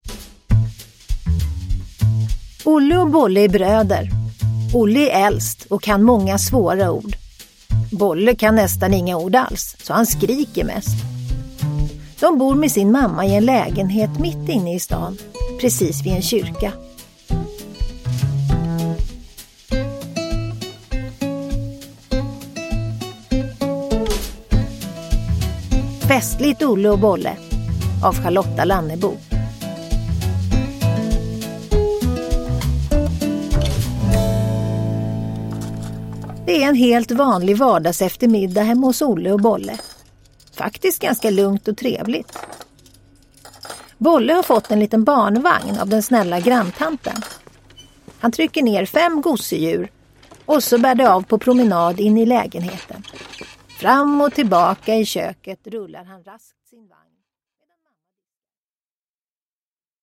Festligt Olle och Bolle – Ljudbok – Laddas ner
Uppläsare: Tova Magnusson